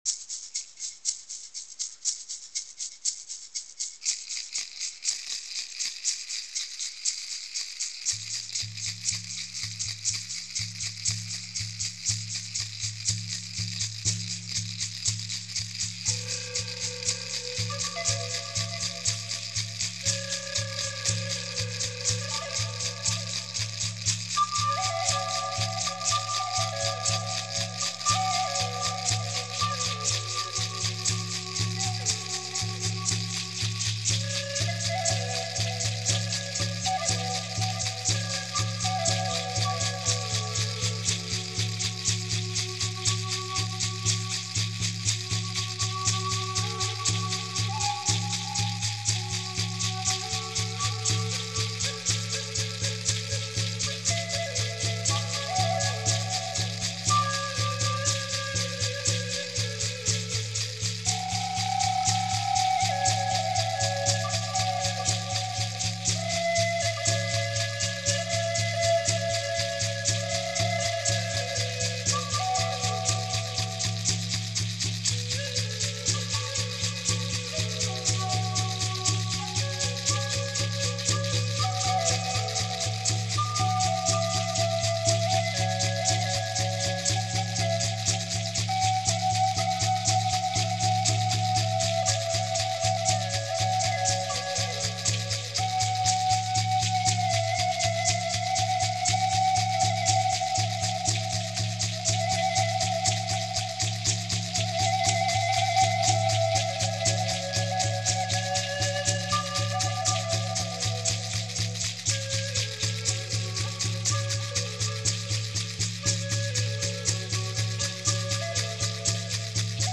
Based on this visit I became inspired to record a trance journey track. Its played with authentic instruments and I played all instruments myself. I love to play native American flute.
shaman-spirit-dance.mp3